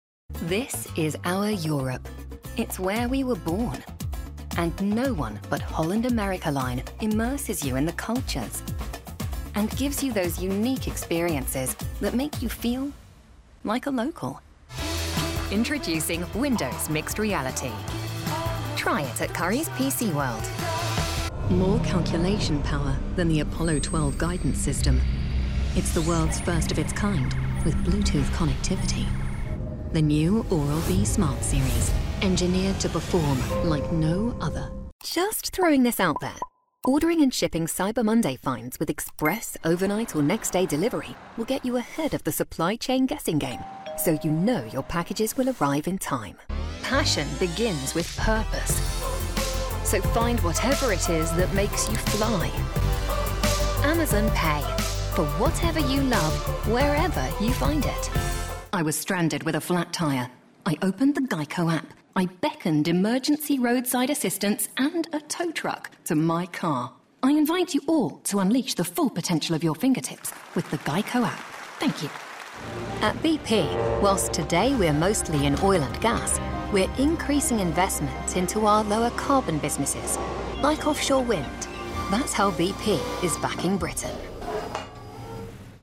Inglés (Británico)
Natural, Maduro, Cálida, Suave, Empresarial
Comercial